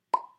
checkmark-pop.mp3